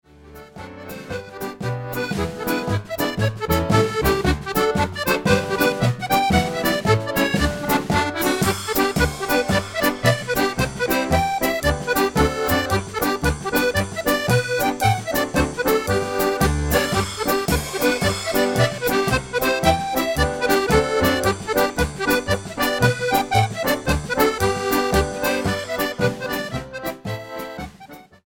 accordion
drums